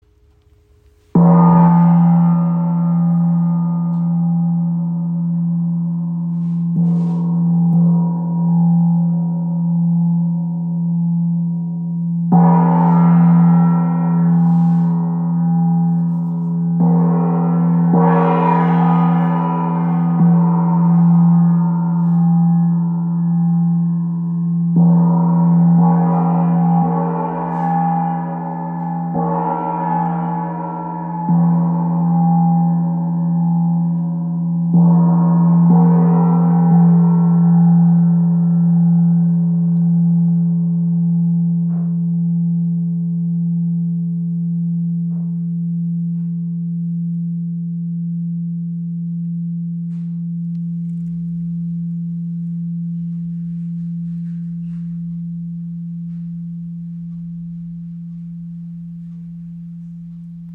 Tam-Tam Gong | Hess Premium Gong | Ø 40 cm im Raven-Spirit WebShop • Raven Spirit
Klangbeispiel
Tam-Tam Gong | Hess Premium Gong | Ø 40 cm Tam-Tam Gong Ø 40 cm Klein und handlich zum in der Hand spielen Variantenreiche Klangentwicklung Hess® Premium-Qualität Die besten, handverlesenen Gongs in Hess® Premium-Qualität.